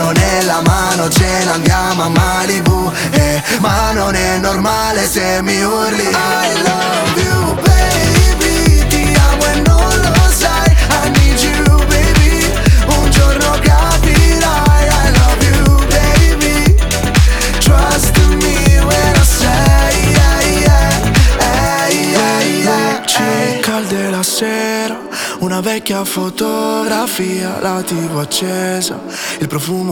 Жанр: Поп